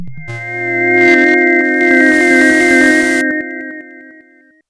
a sound is played.